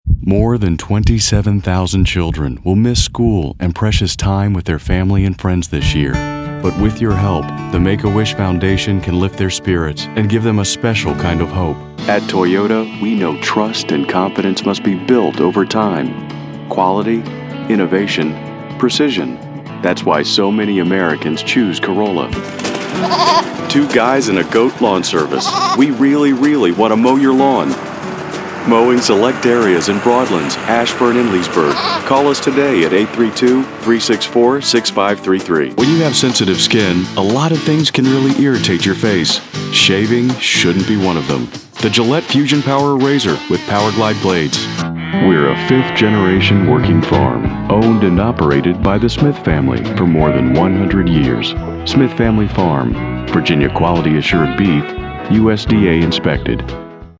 mid-atlantic
Sprechprobe: Werbung (Muttersprache):